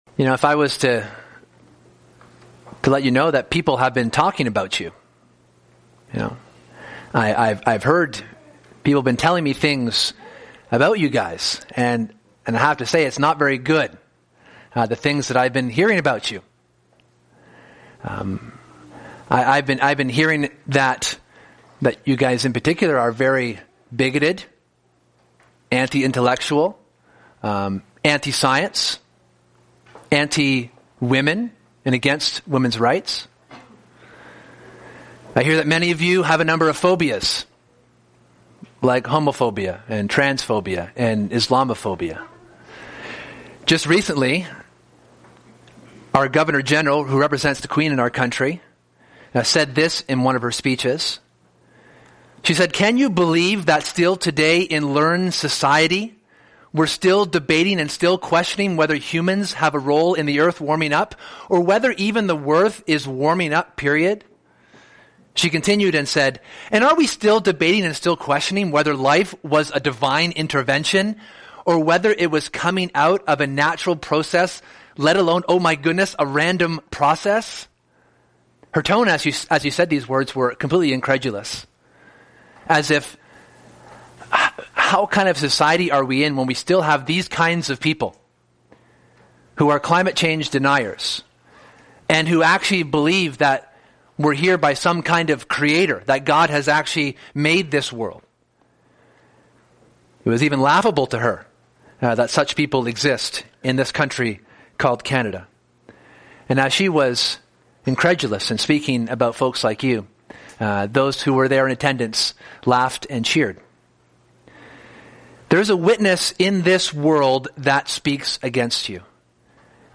This book, and thus this sermon series, explores the nature and character of God.